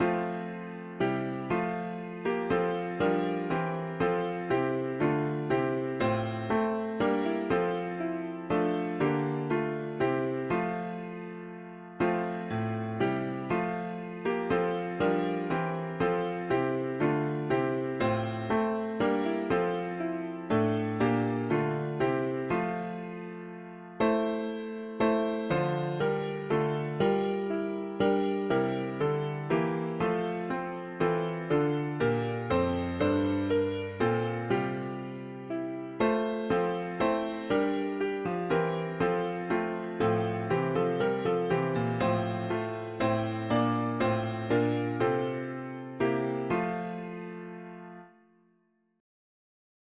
Israel’s strength and co… english christian 4part chords
Key: F major Meter: 87.87 D